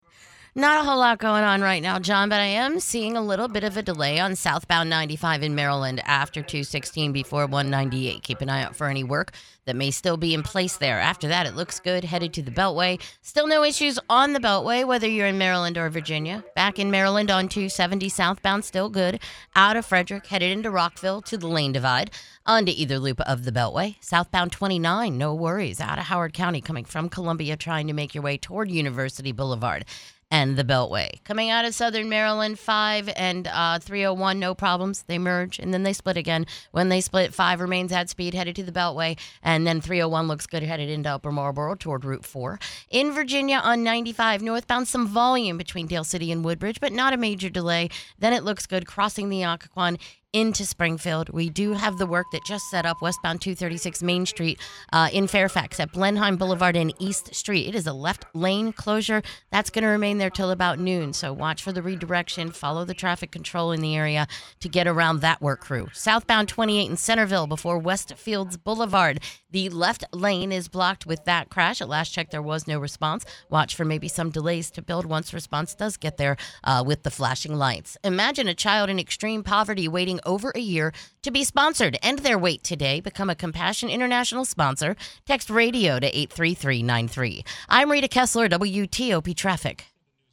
Recent Traffic Report.